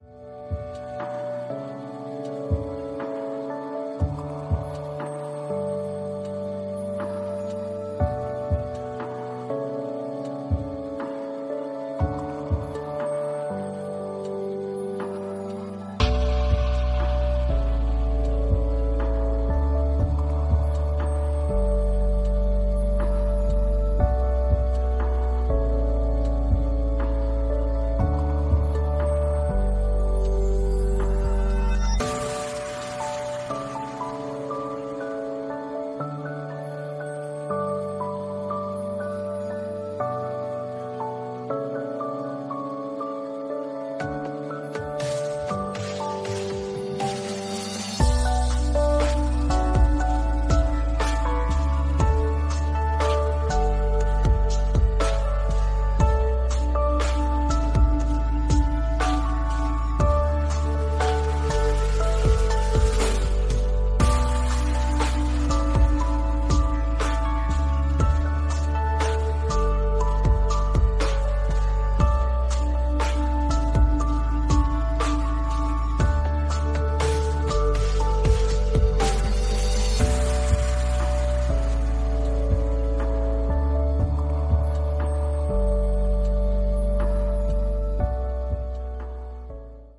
Ambient_0328_7_fade.mp3